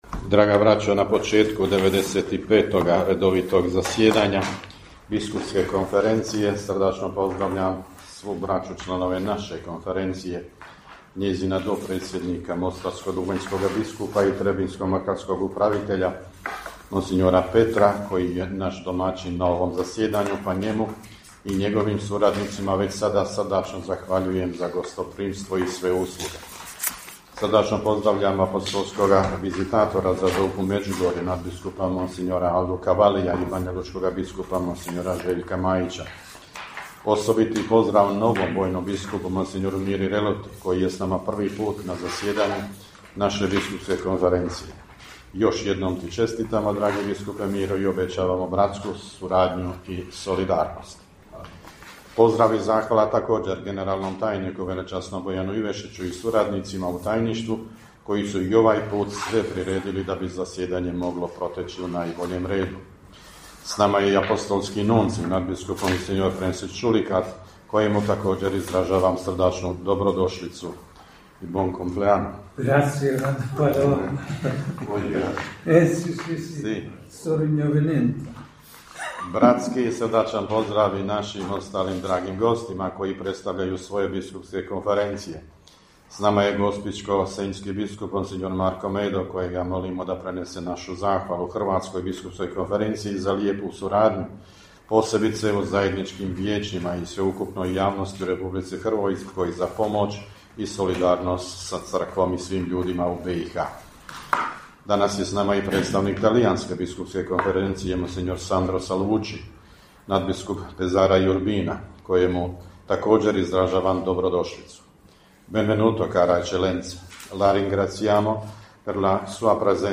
U svom uvodnom govoru predsjednik BK BiH nadbiskup Vukšić pozdravio je sve sudionike, a osobit pozdrav je uputio novom vojnom biskupu u BiH mons. Miri Reloti istaknuvši da je to prvo zasjedanje BK BiH na kojemu on sudjeluje.